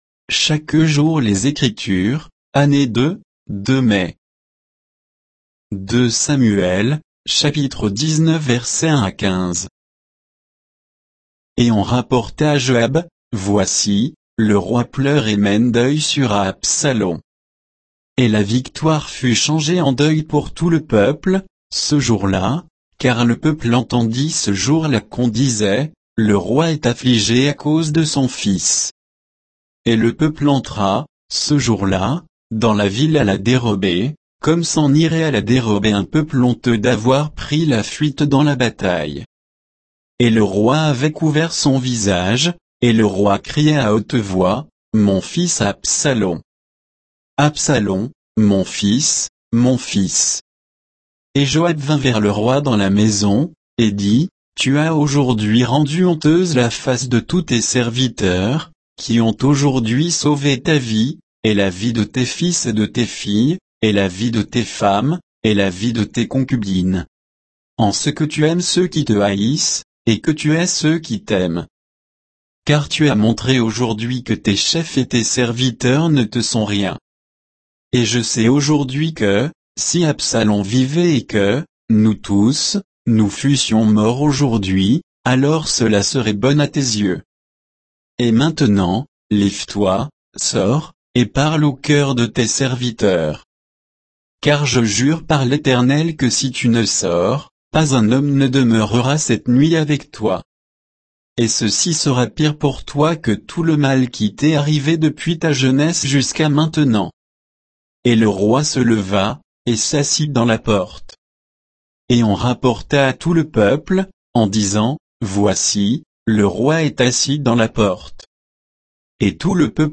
Méditation quoditienne de Chaque jour les Écritures sur 2 Samuel 19